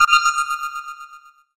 Звук бонуса: лишнее очко